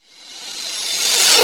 REVERSCYM1-R.wav